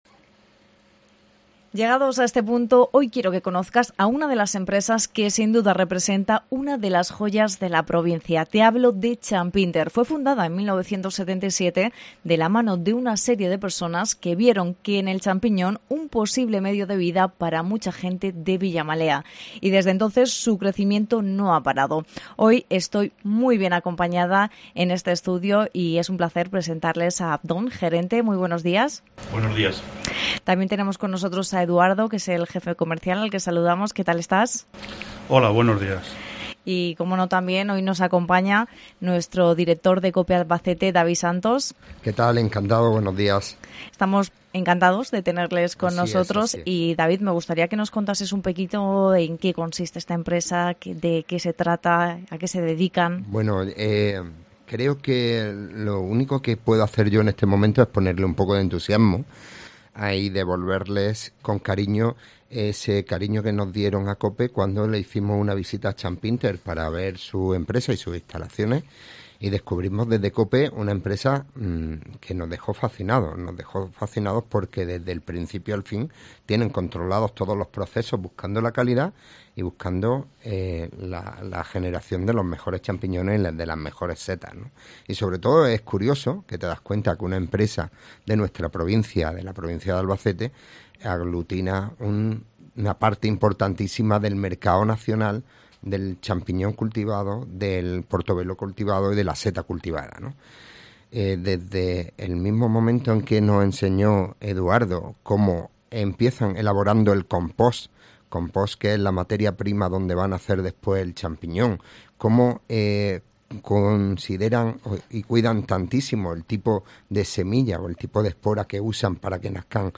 DALE AL PLAY! 00:00 Volumen Descargar Comparte en: Copiar enlace Descargar ENTREVISTA CHAMPINTER -